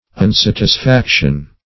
Unsatisfaction \Un*sat`is*fac"tion\
unsatisfaction.mp3